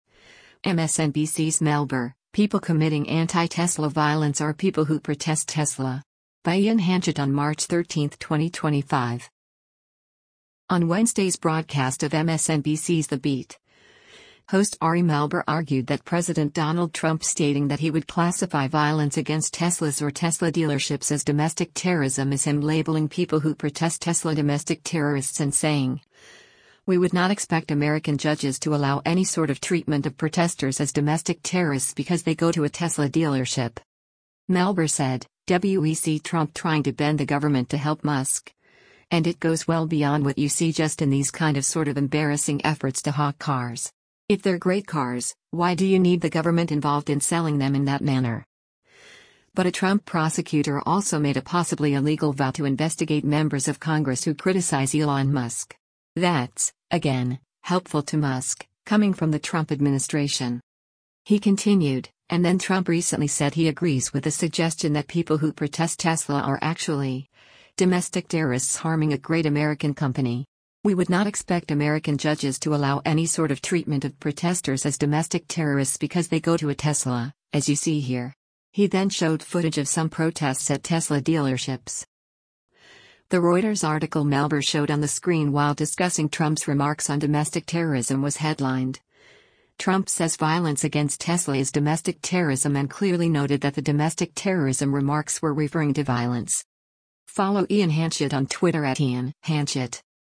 On Wednesday’s broadcast of MSNBC’s “The Beat,” host Ari Melber argued that President Donald Trump stating that he would classify violence against Teslas or Tesla dealerships as domestic terrorism is him labeling “people who protest Tesla” domestic terrorists and saying, “We would not expect American judges to allow any sort of treatment of protesters as domestic terrorists because they go to a Tesla” dealership.